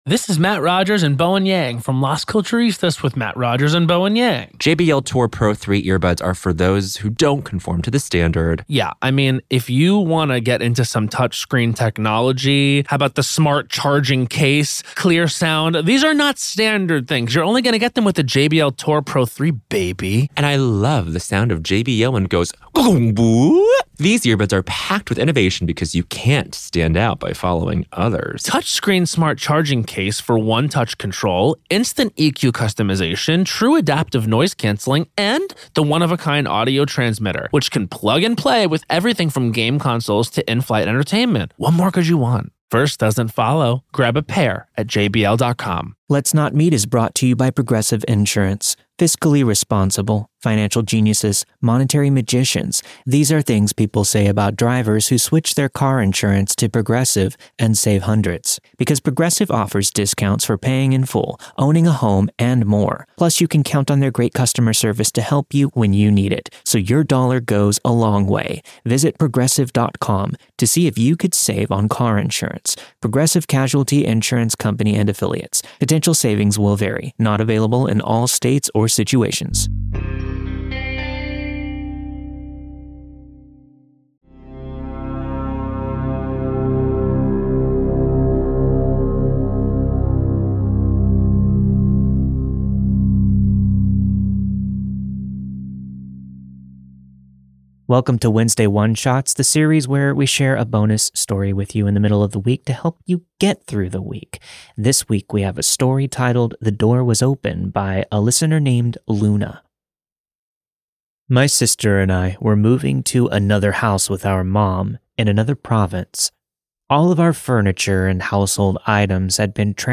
The story you've heard this week was narrated and produced with the permission of its respective author.